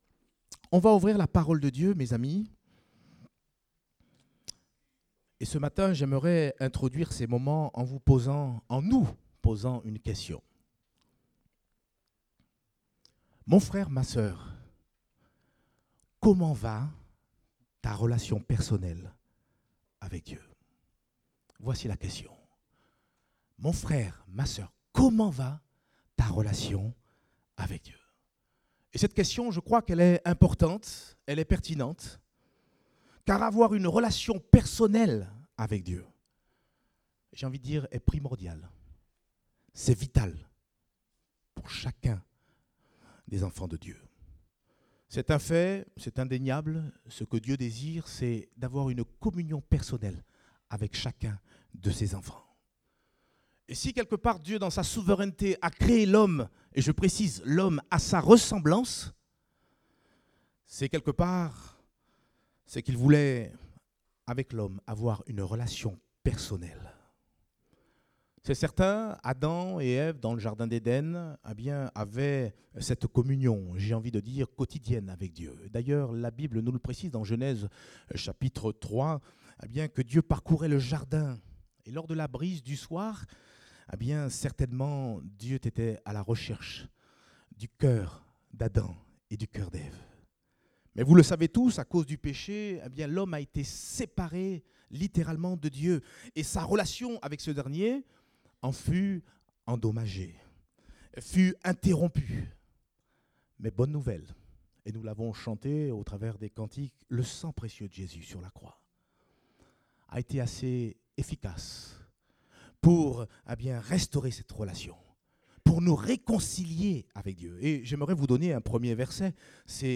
Date : 24 septembre 2017 (Culte Dominical)